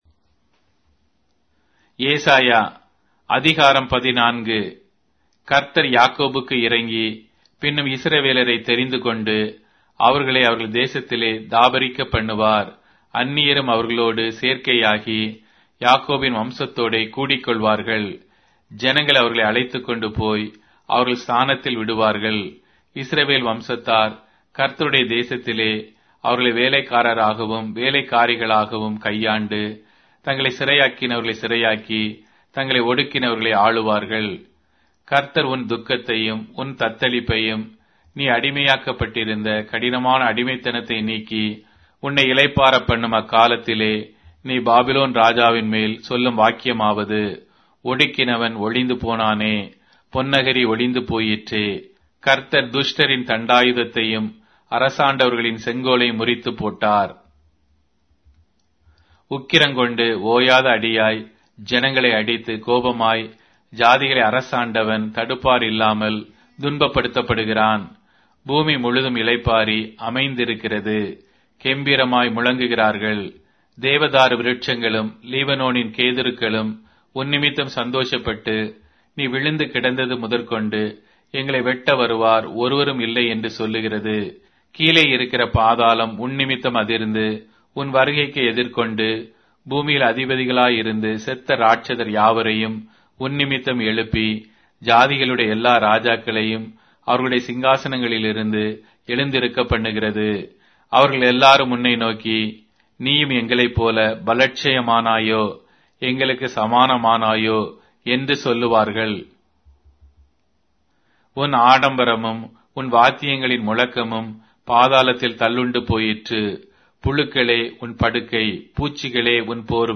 Tamil Audio Bible - Isaiah 57 in Ncv bible version